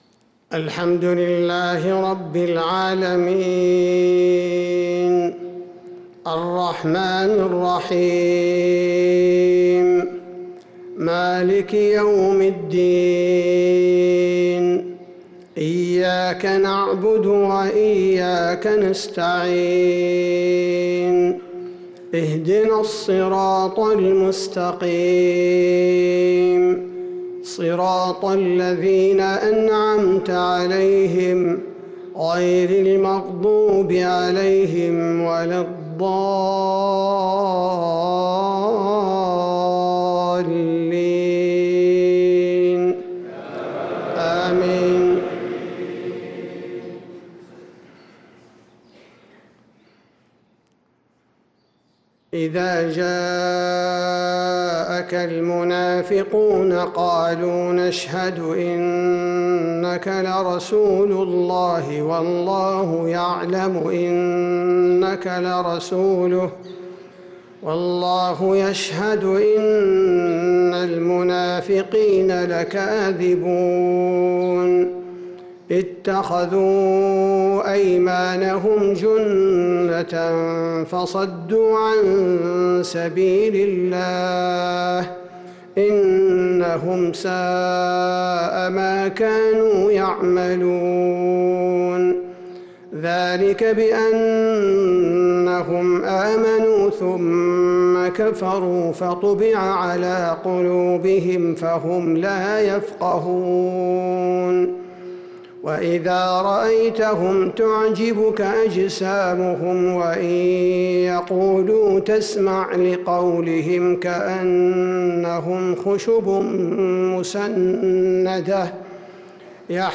فجر الخميس 6-9-1446هـ سورة المنافقون كاملة | Fajr prayer Surat al-Munafiqun 6-3-2025 > 1446 🕌 > الفروض - تلاوات الحرمين